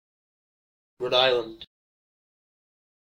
Ääntäminen
Rhode Island US GA: IPA : /ˌɹoʊd ˈaɪ.lənd/